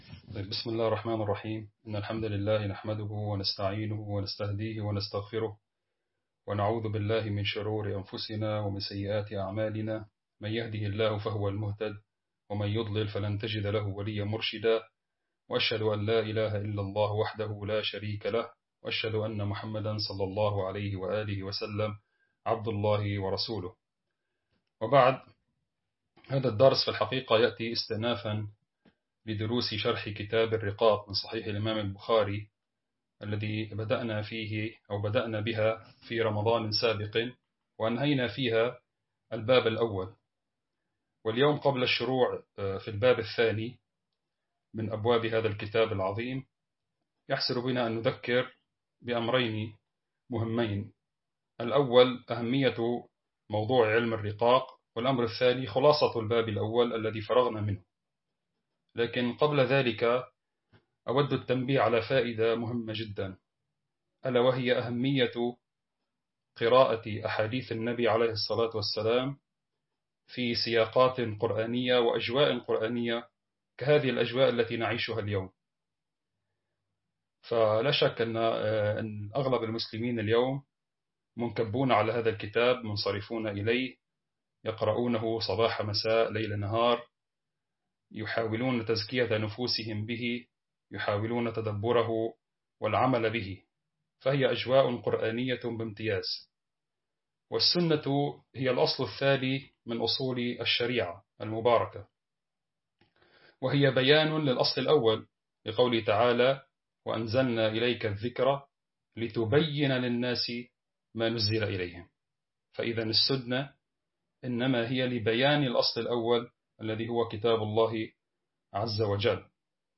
درس عام online